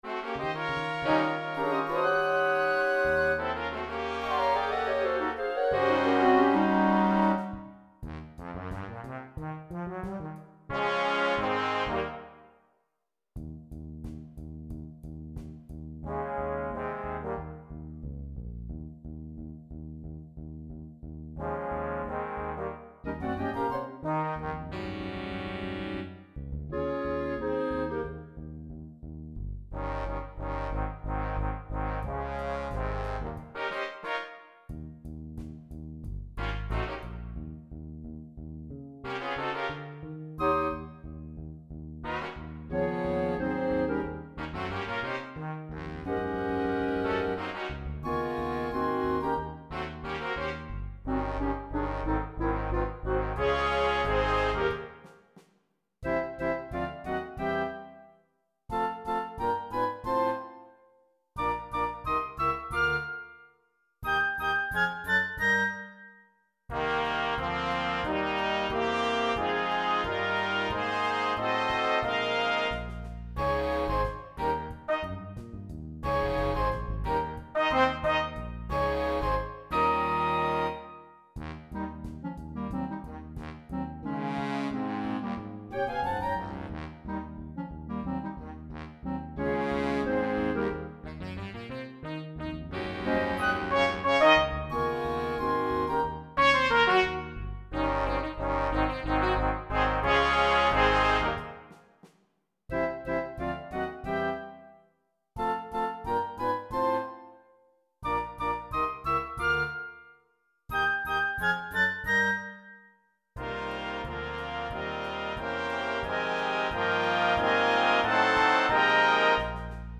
Flöjt 1      Flöjt 2       Altflöjt
Klarinett 1      Klarinett 2    Klarinett 3        Basklarinett
Altsax 1   Altsax 2       Tenorsax 1      Tenorsax 2       Barytonsax
Trumpet 1    Trumpet 2     Trumpet 3     Trumpet 4
Trombon 1    Trombon 2     Trombon 3      Trombon 4       Bastrombon
Bas          Gitarr         Piano       Trumset